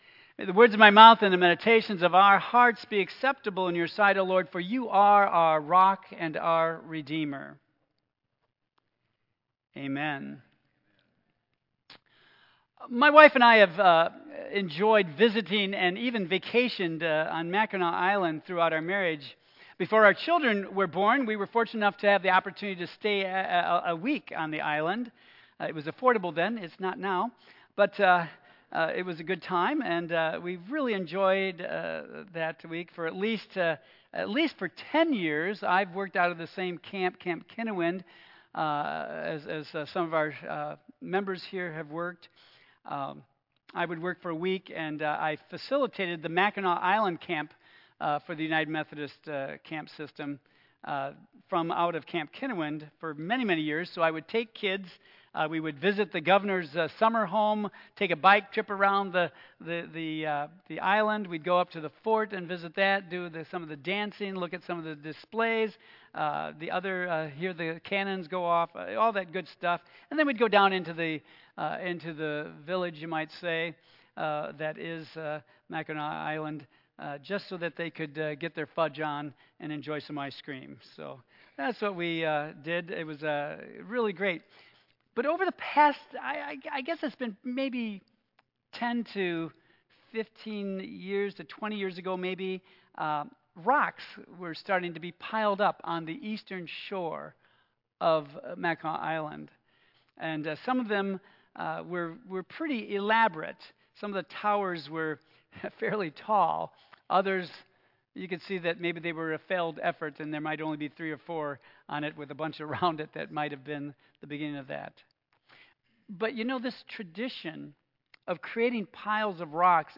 All Saints’ Sunday Rose Memorial Service This special message illustrates how we mark special places and times in our lives.
Tagged with Michigan , Sermon , Waterford Central United Methodist Church , Worship